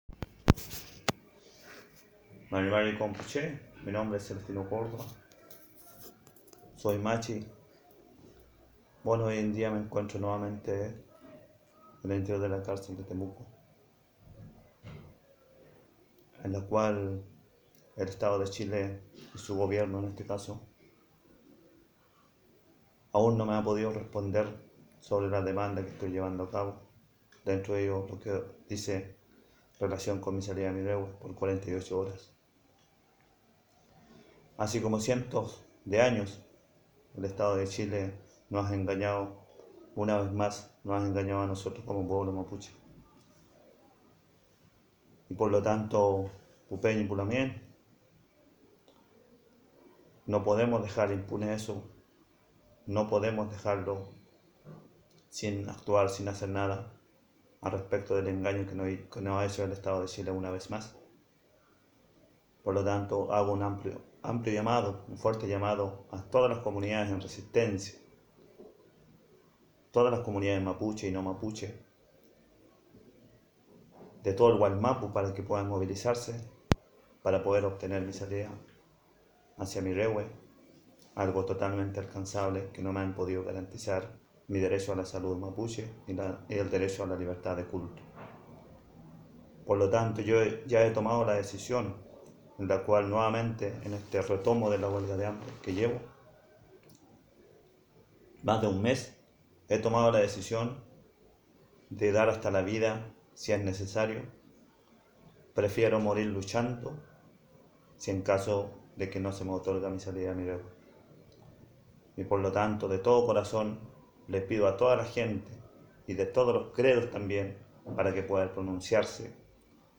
Audio comunicado, palabras del Machi desde la cárcel de Temuco.